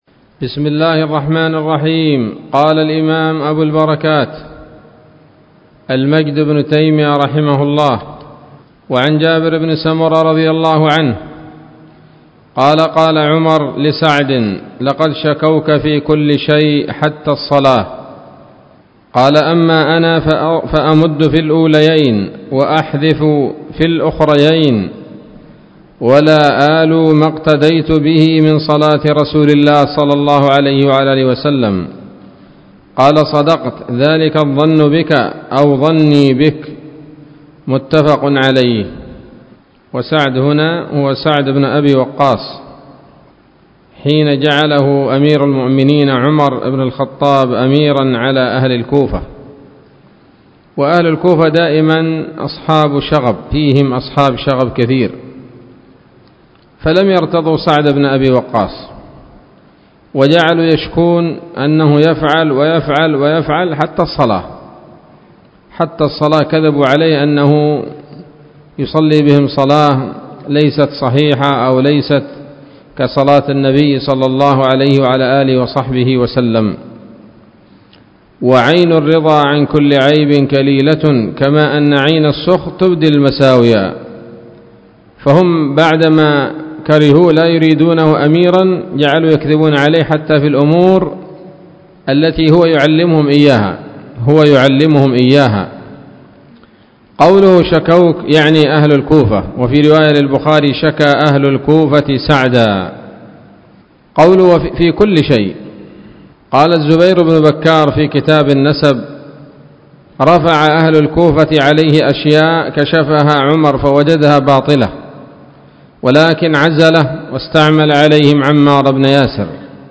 الدرس الثاني والأربعون من أبواب صفة الصلاة من نيل الأوطار